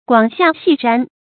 廣夏細旃 注音： ㄍㄨㄤˇ ㄒㄧㄚˋ ㄒㄧˋ ㄓㄢ 讀音讀法： 意思解釋： 高大的房屋，精致的氈毯。